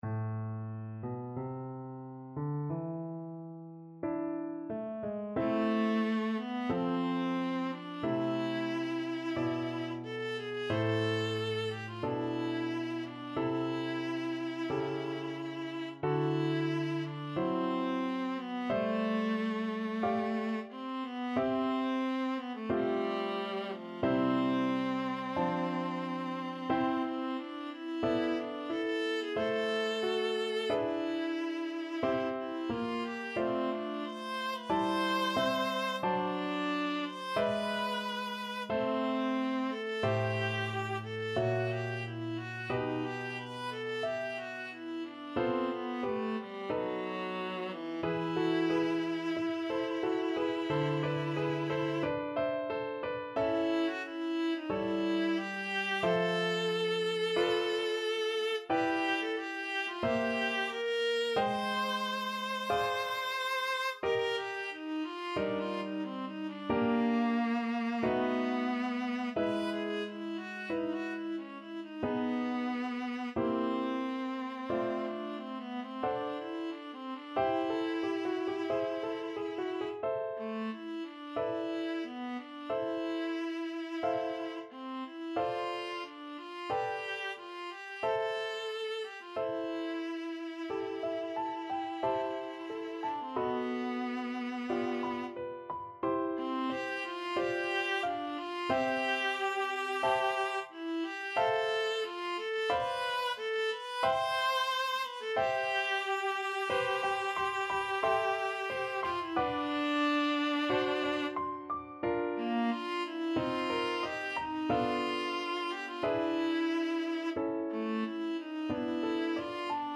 Adagio, molto tranquillo (=60) =45
4/4 (View more 4/4 Music)
Classical (View more Classical Viola Music)